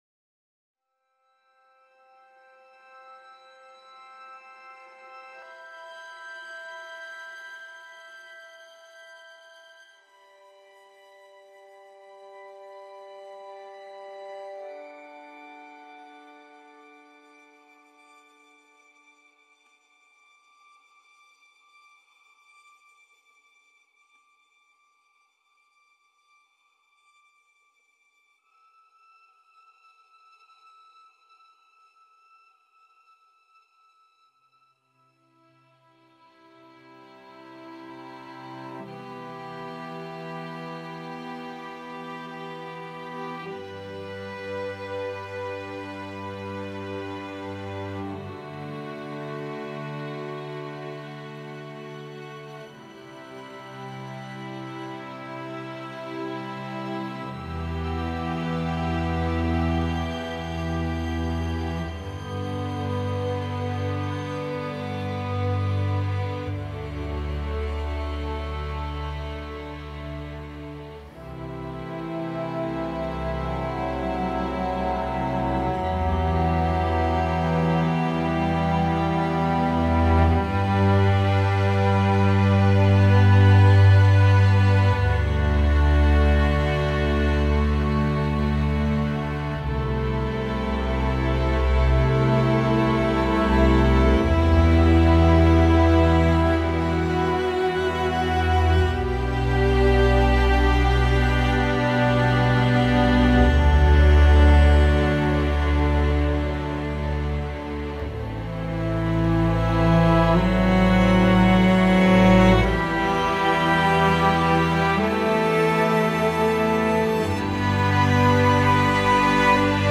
Instrumentation: strings only